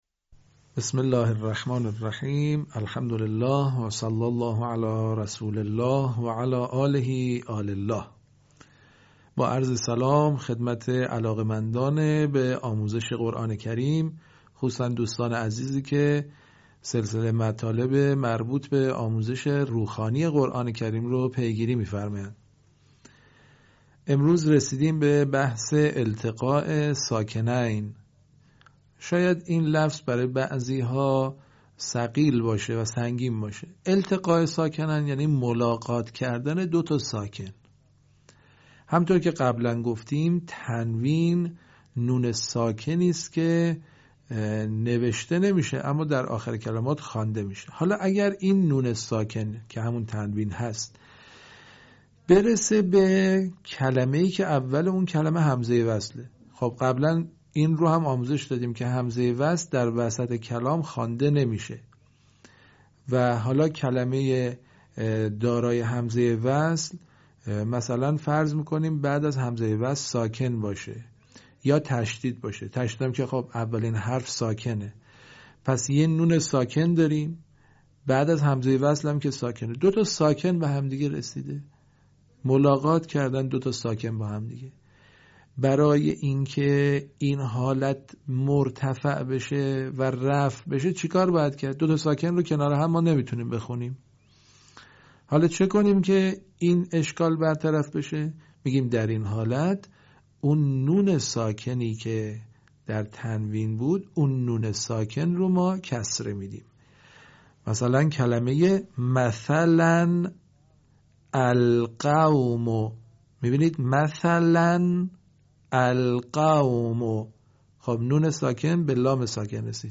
صوت | آموزش نون ساکن در روخوانی و روانخوانی قرآن